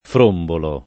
frombolare v.; frombolo [ fr 1 mbolo ]